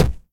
Footstep.wav